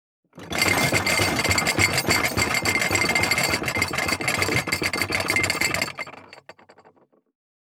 180,効果音,環境音,BGM,地震,引っ越し,荷物運び,段ボール箱の中身,部署移動,
効果音荷物運び